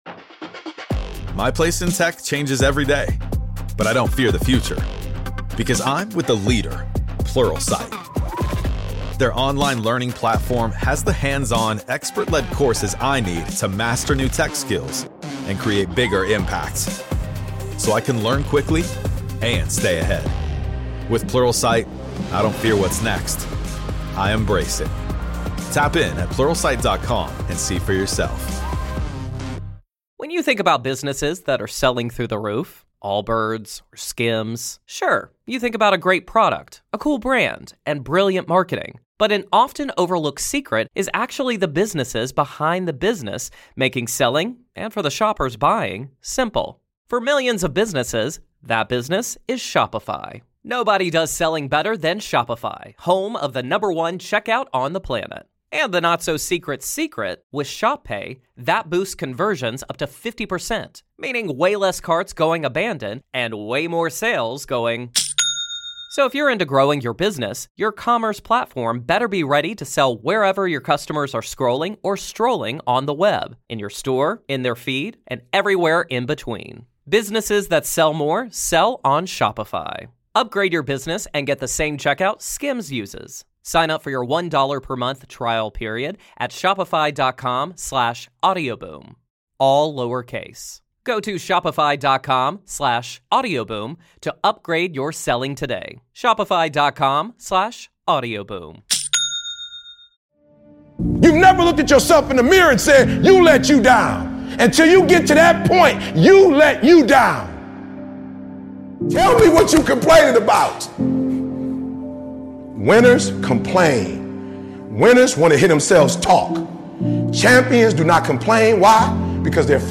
You are your only competition. Don't compare yourself to others, compare yourself to who you were yesterday—one of the Best Motivational Speeches featuring Eric Thomas.